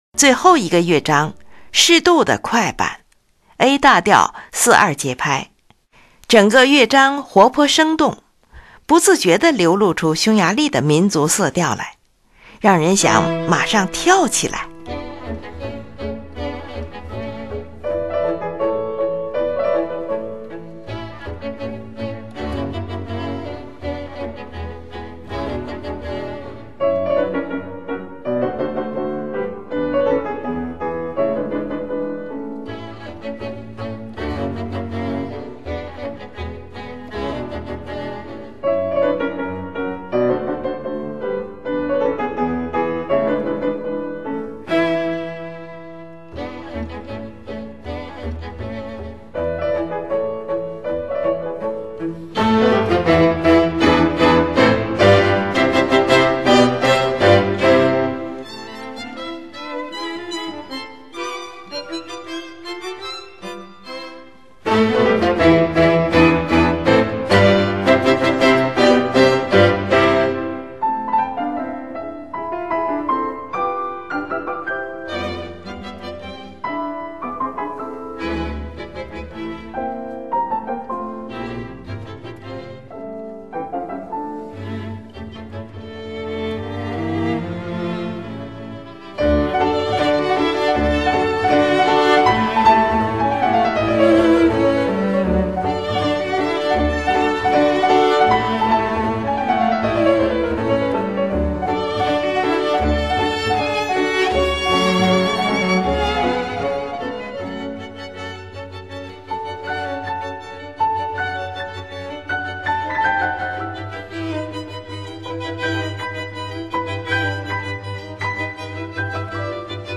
in A Major